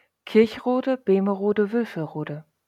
Kirchrode-Bemerode-Wülferode (German pronunciation: [kɪʁçˈʁoːdə beːməˈʁoːdə vʏlfəˈʁoːdə]